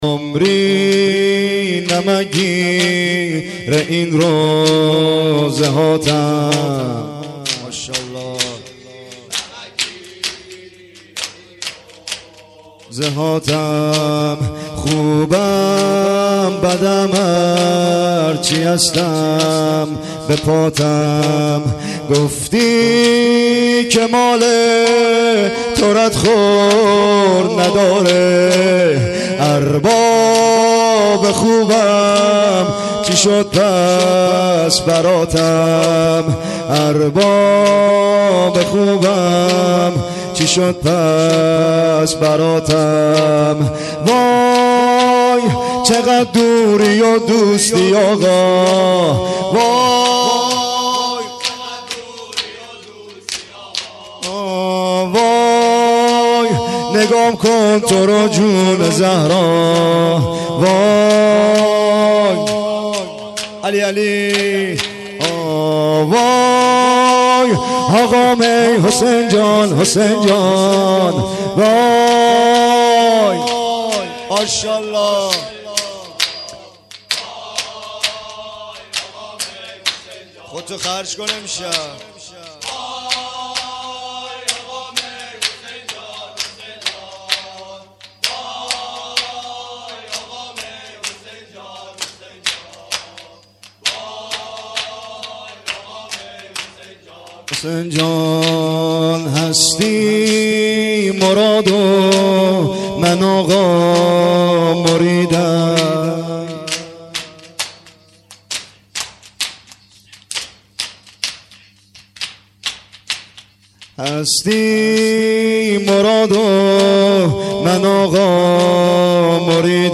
عمری نمک گیر این روضه هاتم . . . (سینه زنی/سه ضرب)